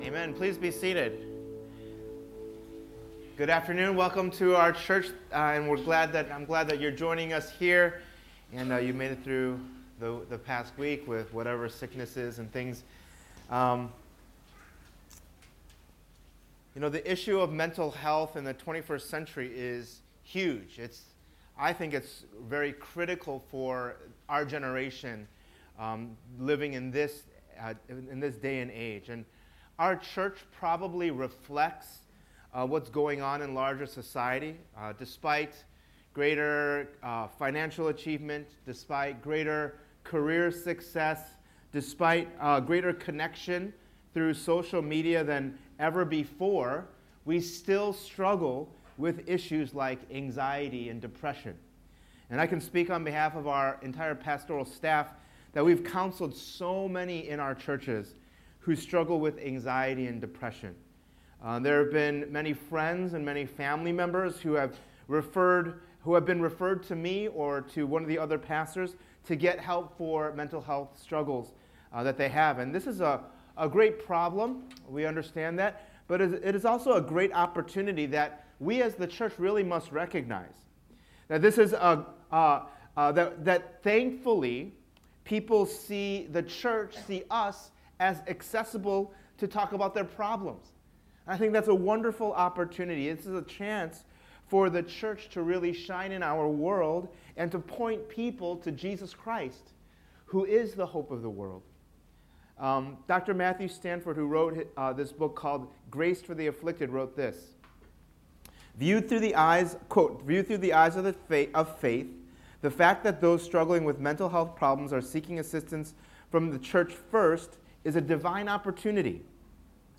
“When You’re Down” is a two-part sermon that will present a biblical approach to understanding mental health, how to handle depression and anxiety in one’s own life, and how we as the church can help others who struggle as well.